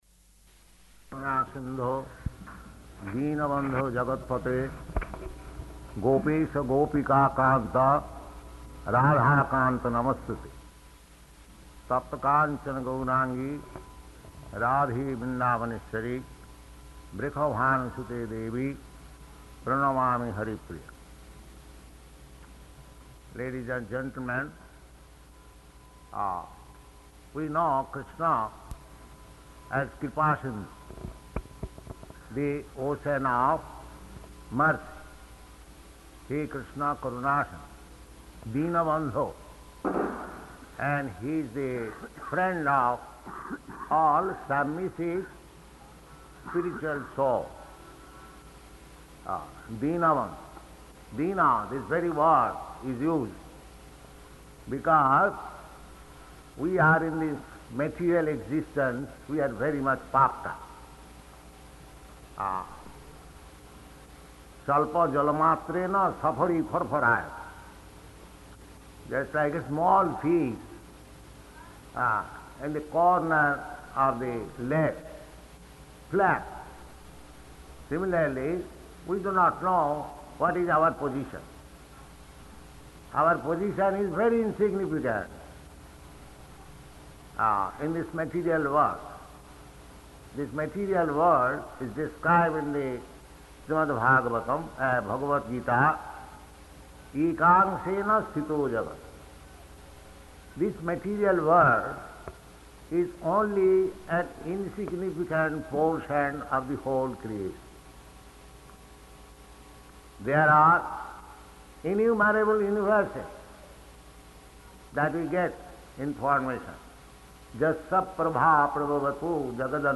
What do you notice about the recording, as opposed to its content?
Lecture at Government Center, Pedagogical Institute [Partially Recorded] Location: Allahabad